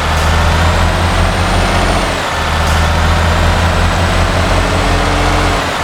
Index of /server/sound/vehicles/lwcars/truck_daf_xfeuro6